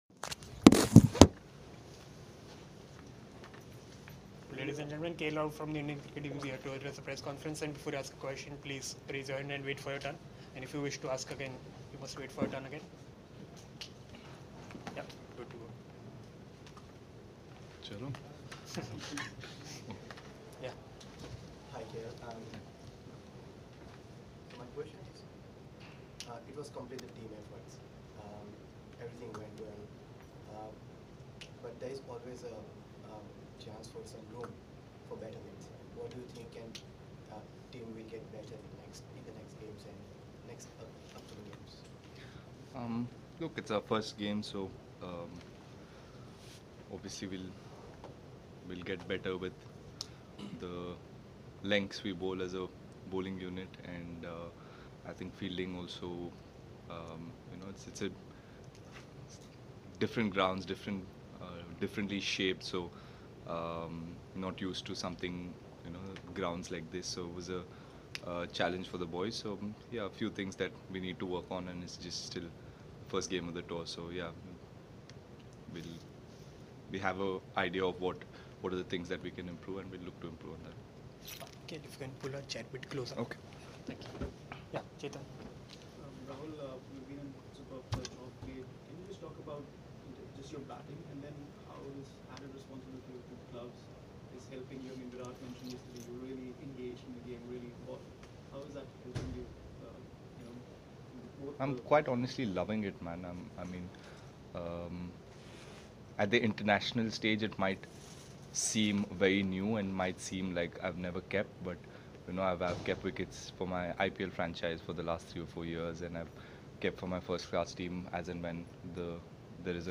KL Rahul spoke to the media in Auckland on Friday after the first T20I against New Zealand.